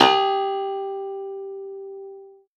53v-pno05-G2.wav